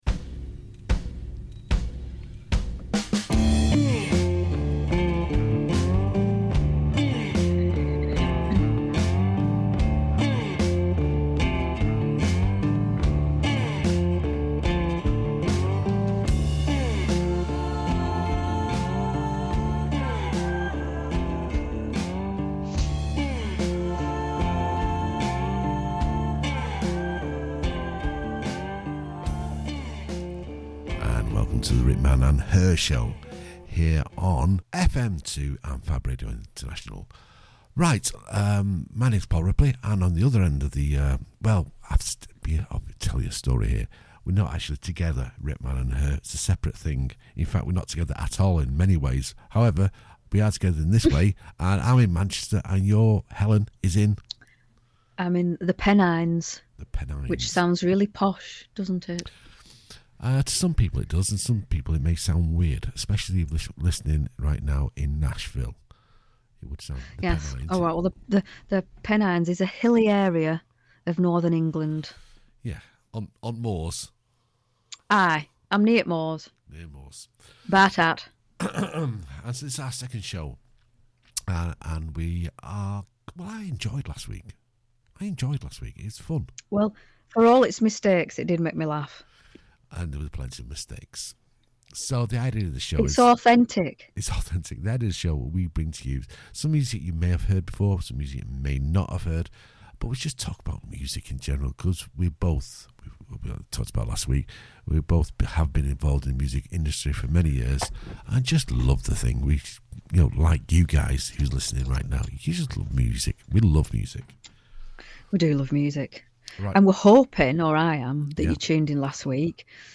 Five songs each, a musical gauntlet thrown down!